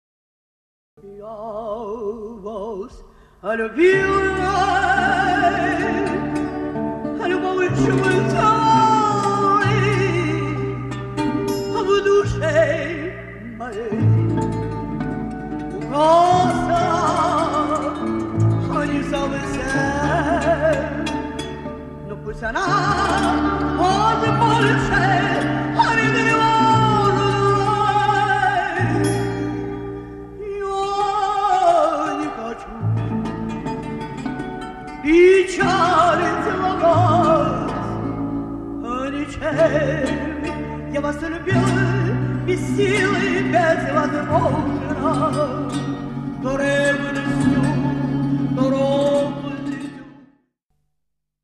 Муз. 16 (2:00) Цыганская певица поет на сцене ресторана